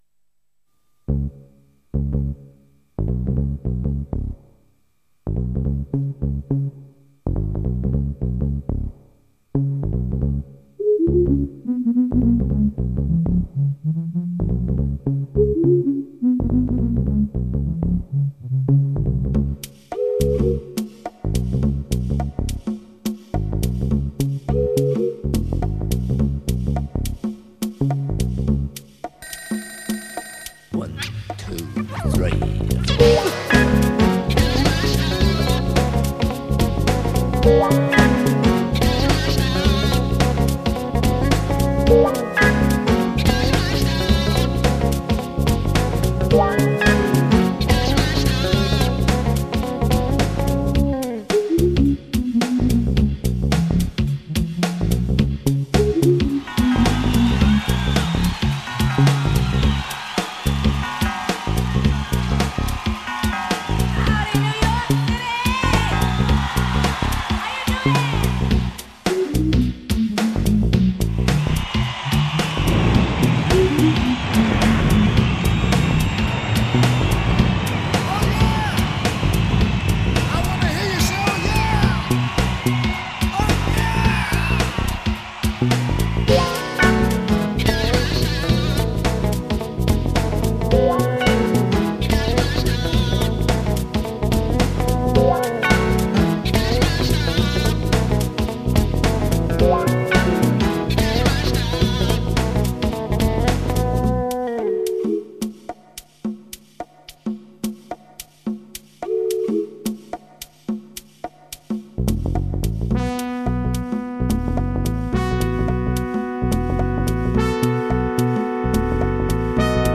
このlibraryにありますのは各芝居のために作曲・演奏・録音した曲ですが、いわゆる"カラオケ"の状態で残っているもので、これだけ聞いても何が何だかよく分からないと思われるため、このような低い階層のページにまとめて収納しています。